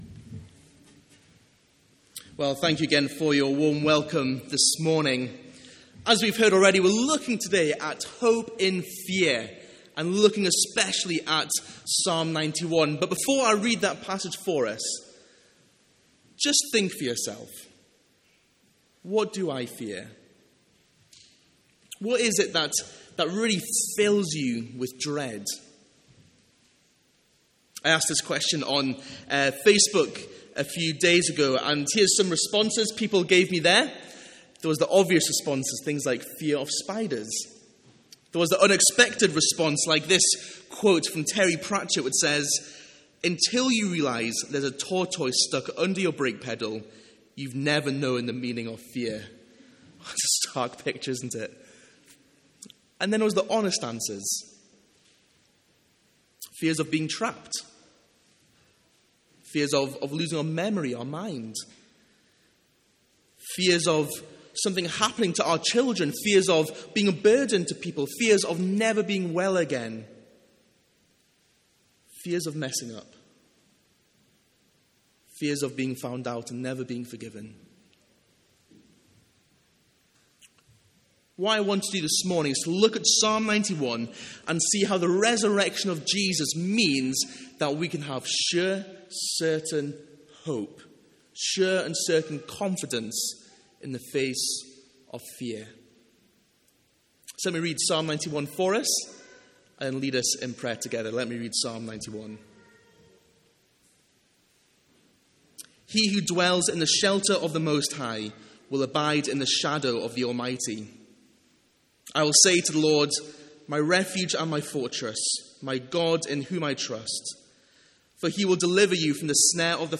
A one off sermon from Psalm 91.